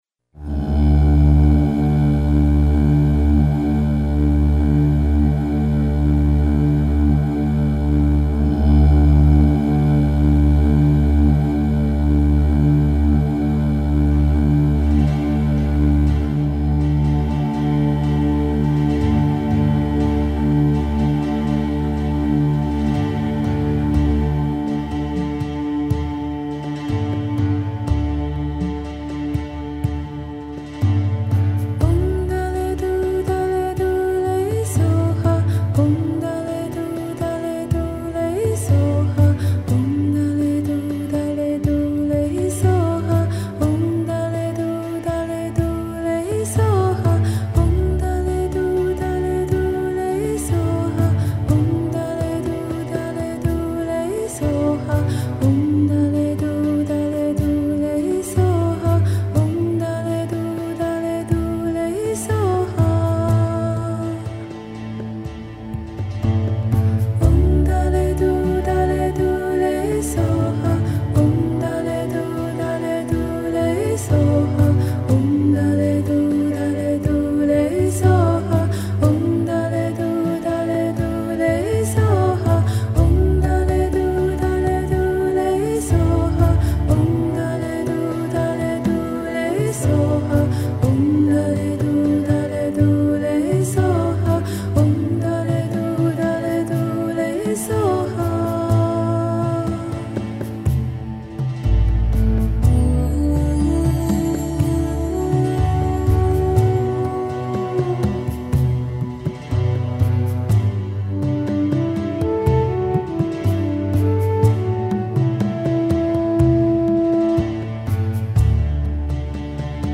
演出：小提琴/ 鋼琴/電吉他/人声